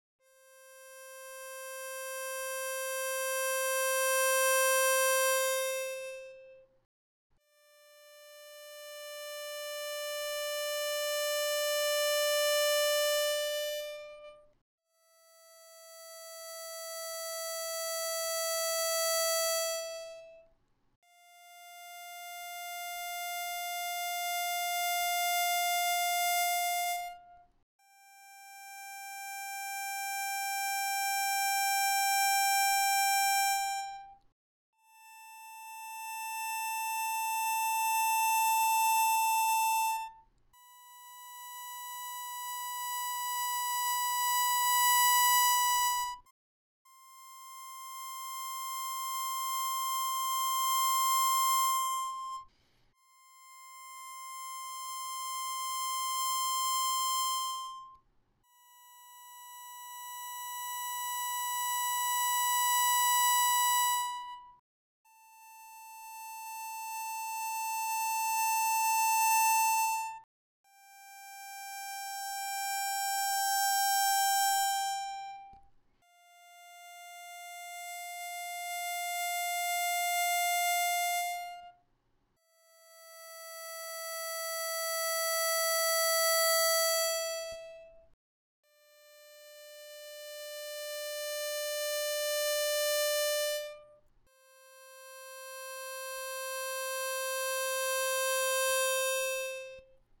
Играть от тихого к среднему (выводя звук с минимальной громкости до средней). Играть гамму «до мажор» +4-4+5-5+6-6-7+7